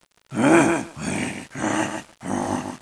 rhino.wav